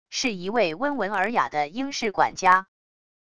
是一位温文尔雅的英式管家wav音频